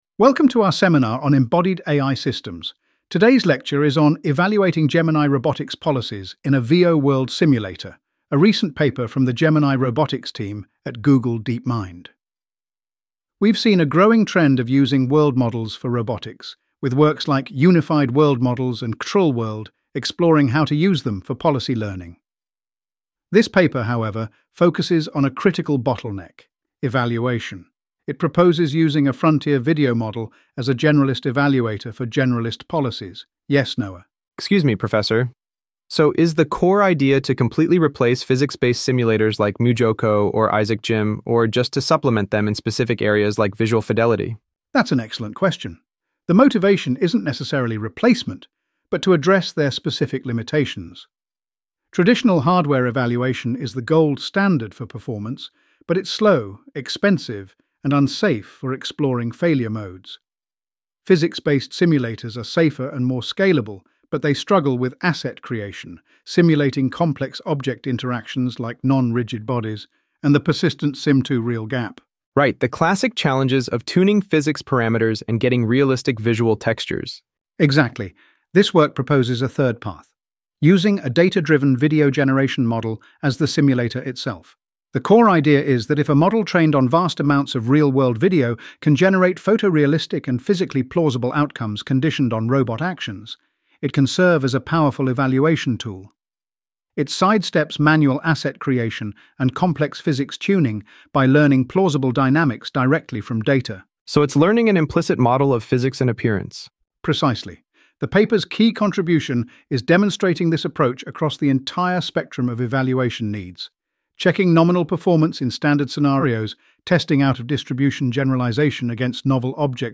AI Audio Lecture + Q&A 0:00